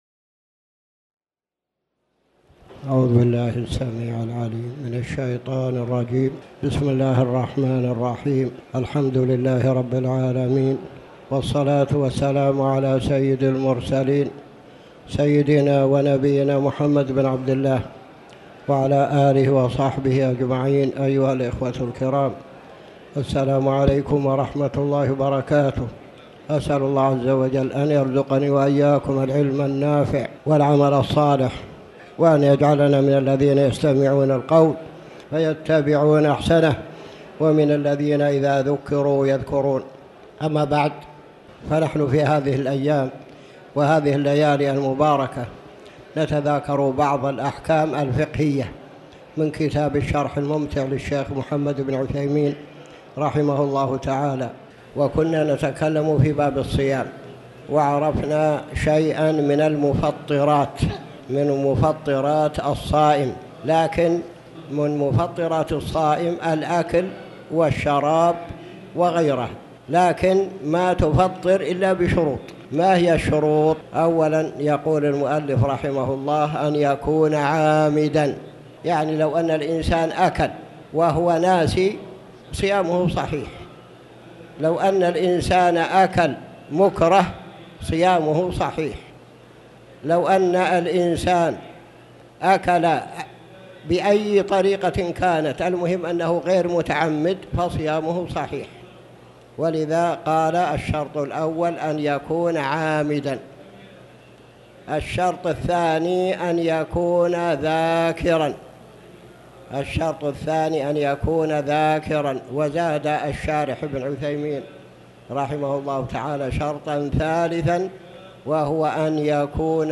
تاريخ النشر ٢٤ جمادى الأولى ١٤٣٩ هـ المكان: المسجد الحرام الشيخ